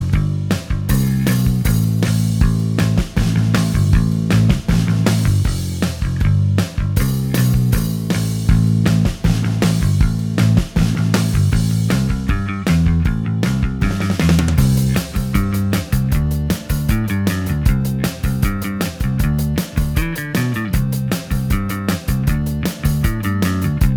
No Two Part Harmonies Pop (1980s) 3:23 Buy £1.50